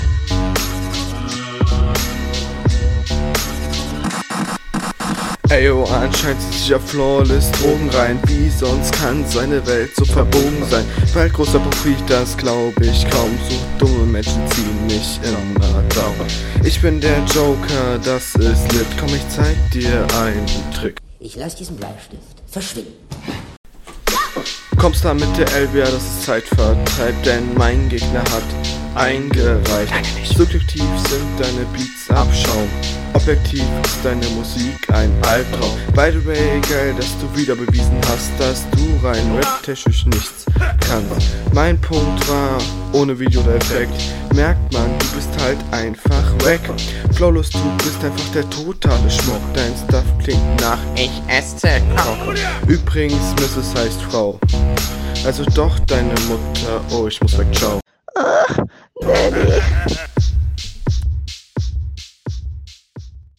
Beat sehr interessant.